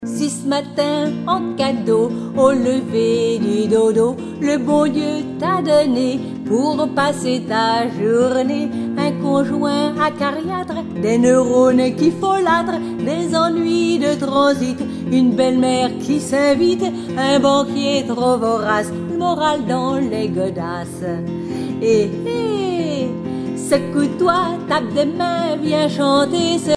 Genre strophique
Pièce musicale éditée ( chanson, musique, discographie,... )